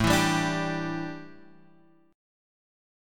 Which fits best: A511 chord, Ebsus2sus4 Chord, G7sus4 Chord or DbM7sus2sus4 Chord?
A511 chord